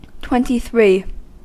Ääntäminen
Ääntäminen US : IPA : [ˌtwɛn.ɾi.ˈθɹi] Tuntematon aksentti: IPA : /ˌtwɛn.ti.ˈθɹi/ IPA : /ˌtwɛn.ti.ˈθɹiː/ Haettu sana löytyi näillä lähdekielillä: englanti Haku uudelleenohjattiin sanaan twenty-three .